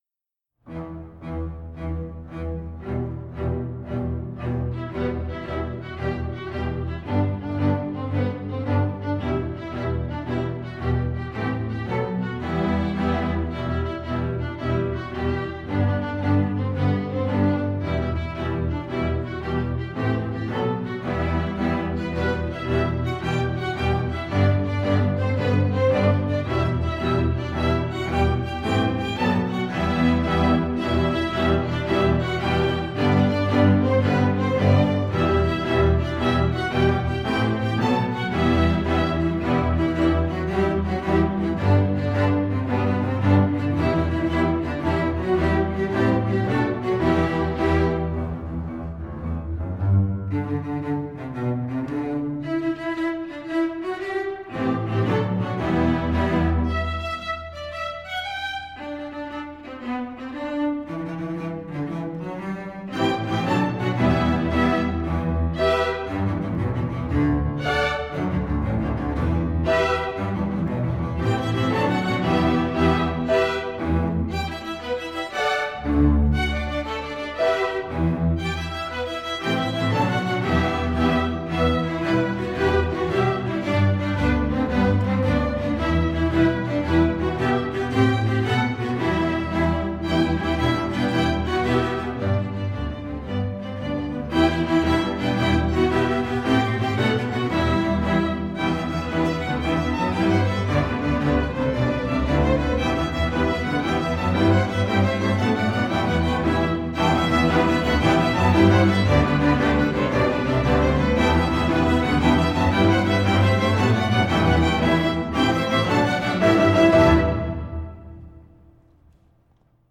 Composer: Irish Folk Song
Voicing: String Orchestra